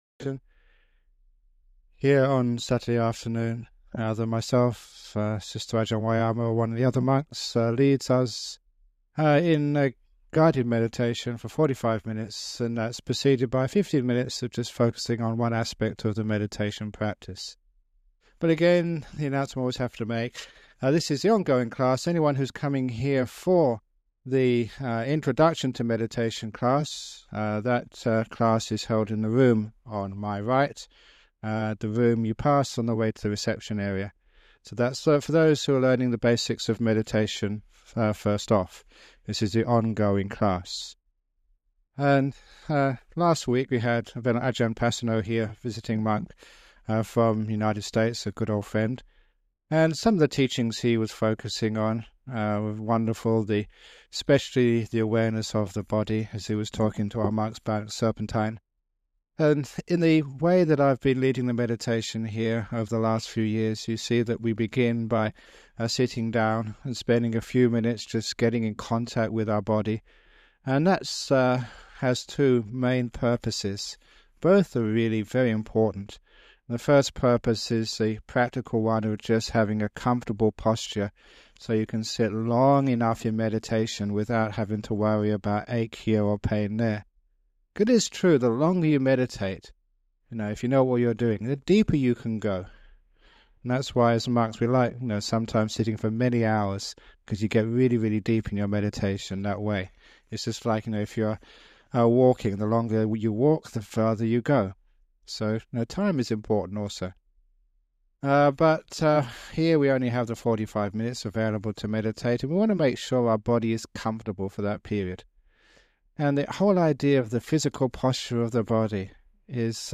It includes a talk about some aspect of meditation followed by a 45 minute guided meditation (starting at the 13 minutes mark).